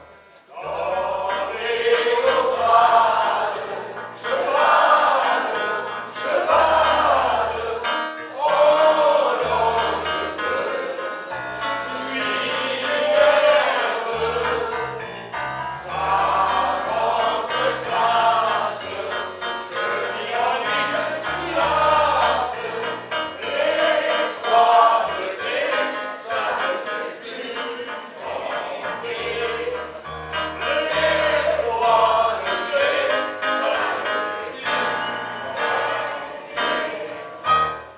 Extrait Festival 2023- Refrain